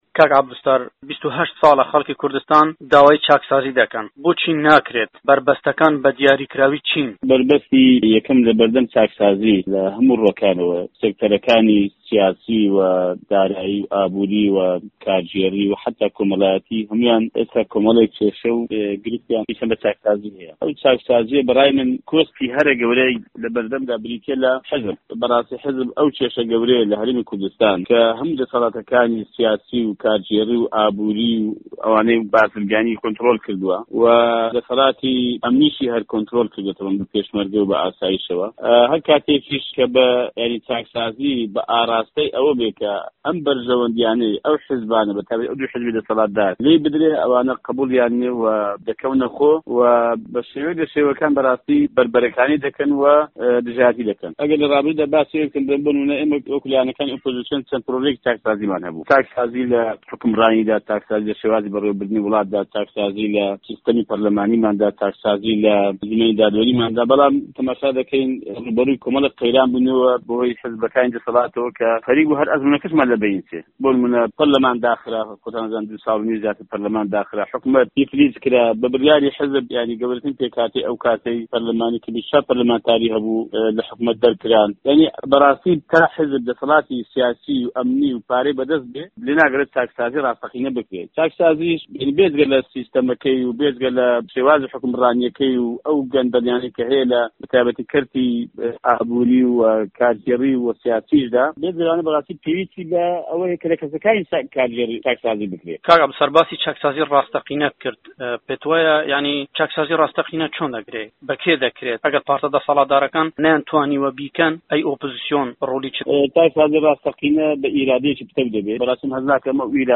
عەبدولستار مەجید سەرۆکی فڕاکسیۆنی کۆمەڵی ئیسلامی کوردستان لە چاوپێکەوتنێکدا لەگەڵ دەنگی ئەمەریکا دەڵێت تا حیزب دەسەڵاتی سیاسی و ئەمنی و پارەی بەدەست بێت لێناگەڕێت چاکسازی ڕاستەقینە بکرێت